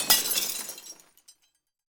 glass_smashable_debris_fall_03.wav